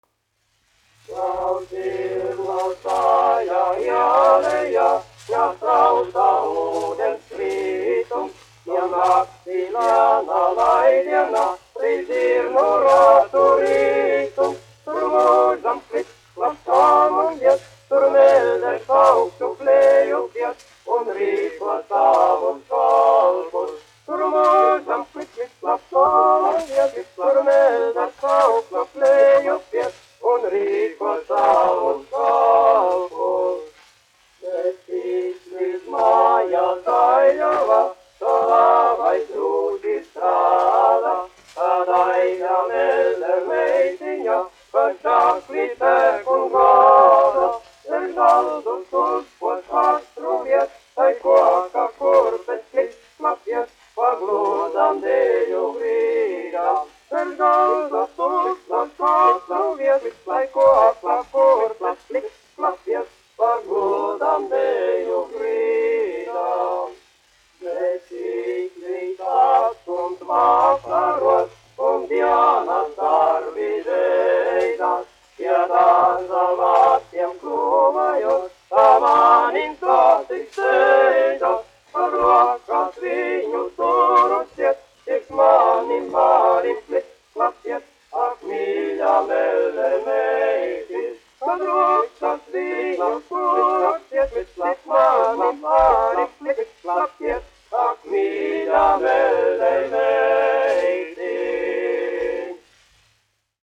1 skpl. : analogs, 78 apgr/min, mono ; 25 cm
Vokālie kvarteti
Populārā mūzika -- Latvija
Skaņuplate
Latvijas vēsturiskie šellaka skaņuplašu ieraksti (Kolekcija)